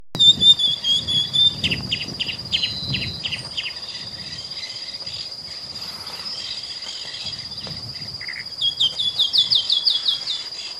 Calandria Grande (Mimus saturninus)
De fondo se escuchan unas cotorras
Localización detallada: Camino Rural
Condición: Silvestre
Certeza: Observada, Vocalización Grabada